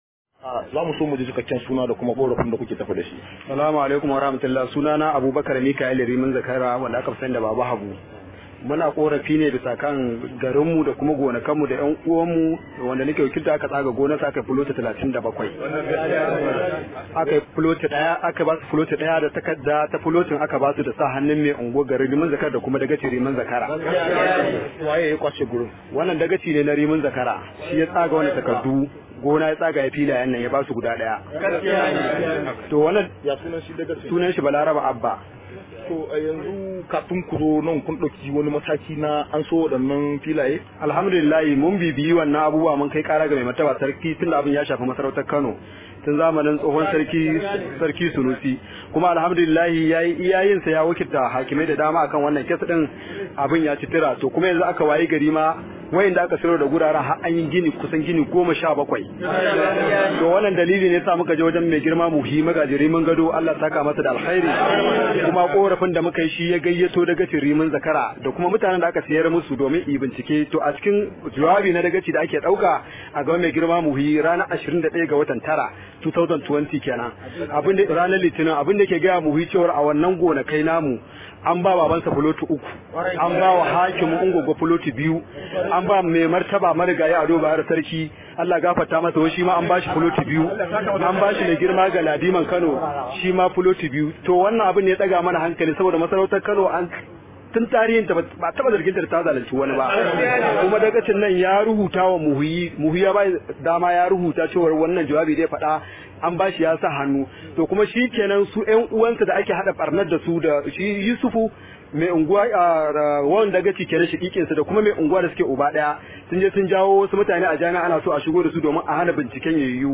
Al’ummar yankin sun koka ne a zantawar su da gidan rediyon Dala a ranar Laraba yayin zantawar su da wakilin mu